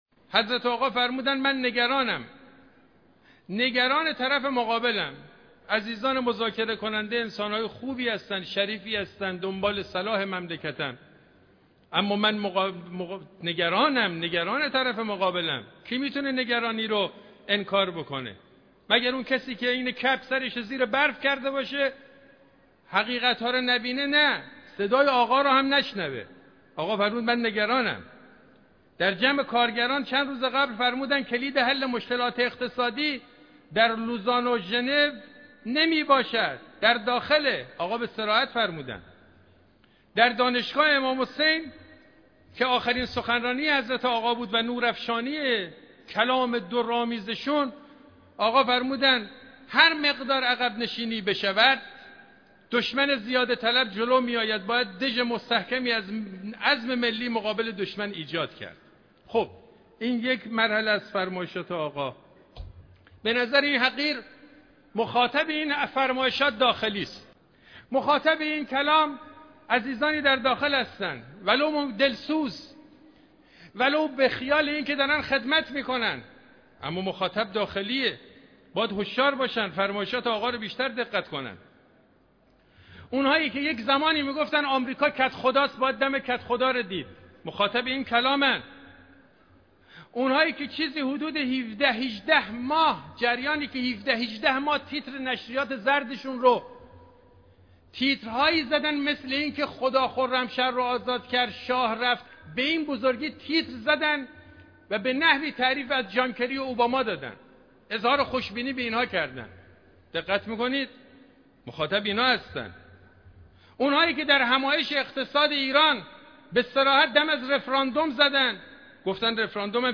به گزارش پایگاه 598 به نقل از رجانیوز، حجت الاسلام والمسلمین میراحمدرضا حاجتی، امام جمعه موقت اهواز، در خطبه های عبادی سیاسی این هفته که در مصلی امام خمینی(ره) اهواز برگزار شد، به نگرانی رهبرمعظم انقلاب از دسیسه های گروه ۵+۱ اشاره کرد و بیان داشت: براساس سخن ولی امر مسلمین در جمع کارگران توجه به اقتصاد مقاومتی کلید حل مشکلات اقتصادی است نه نگاه به دست بیگانگان در لوزان و ژنو و نیویورک!